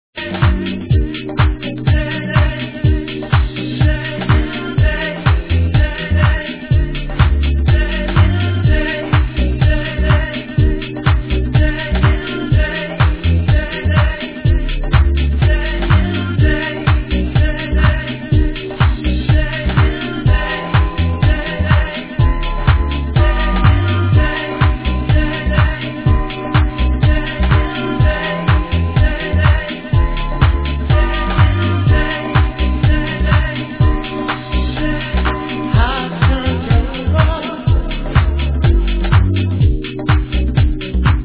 Exclamation Sweet house tune